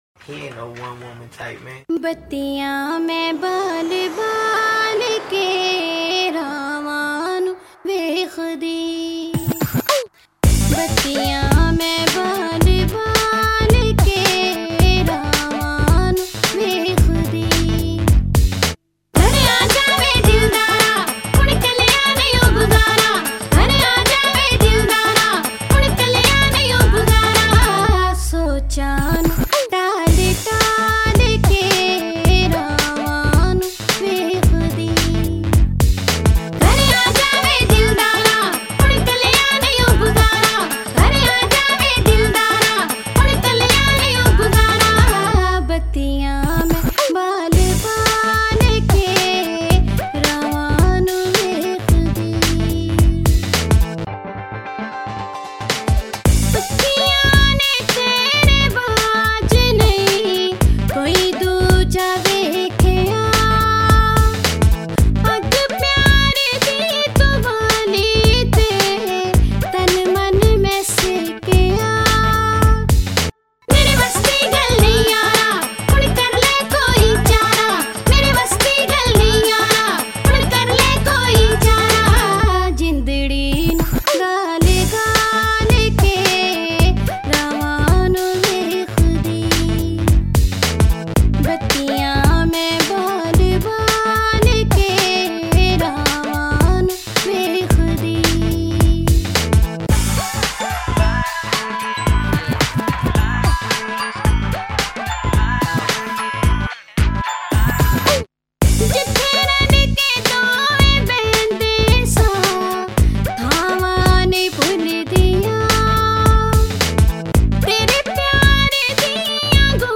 In PuNjaBi StYlE